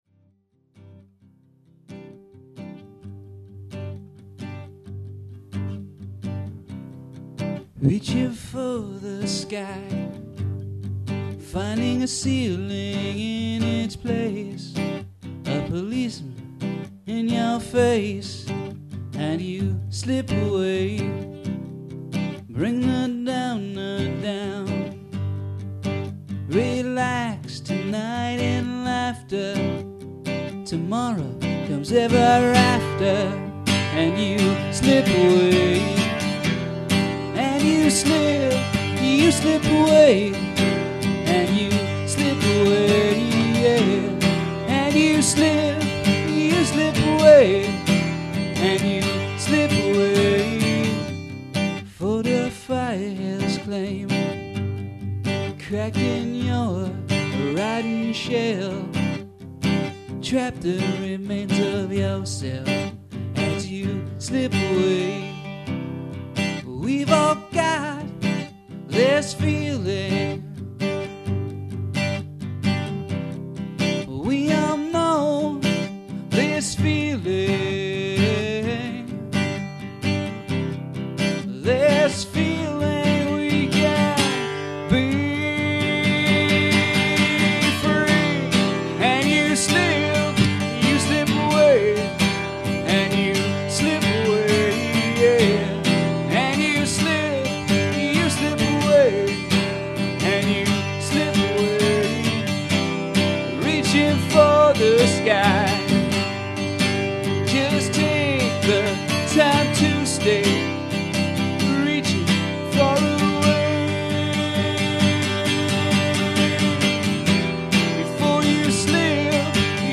alternative rock sound
guitar